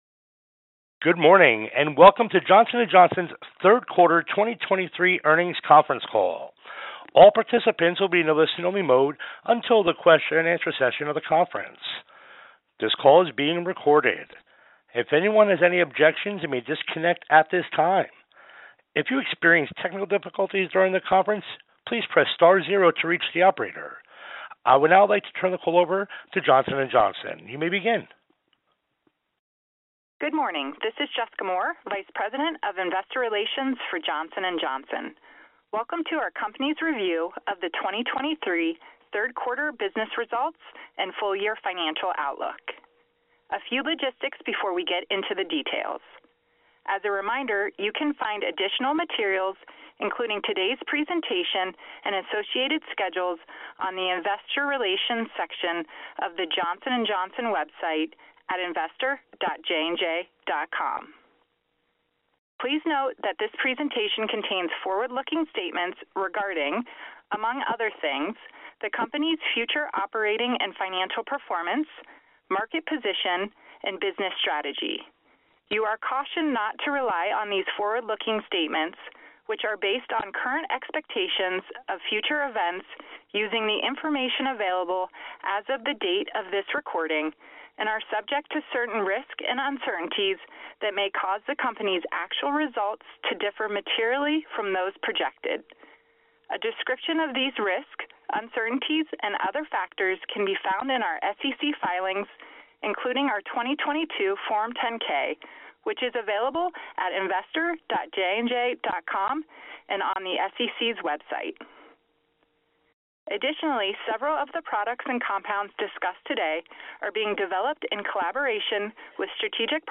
Johnson & Johnson Third Quarter 2023 Earnings Call and Webcast | Johnson & Johnson